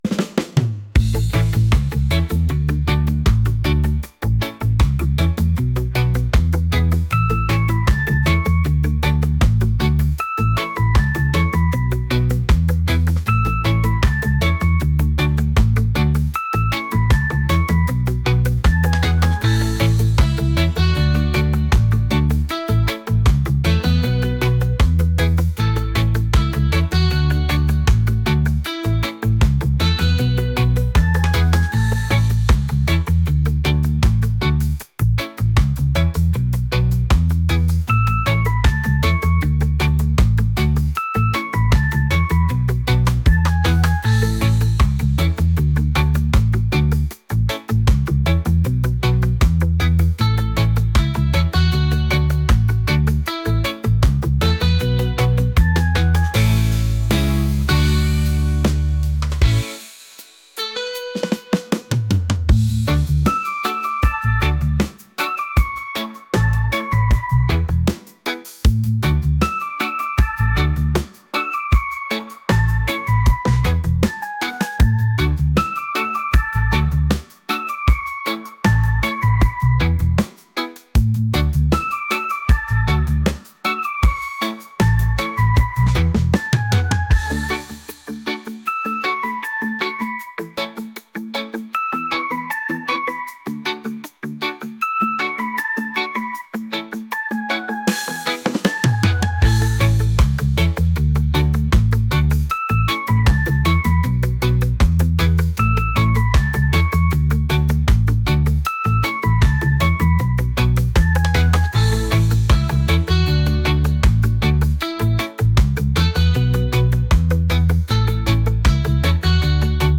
reggae | ska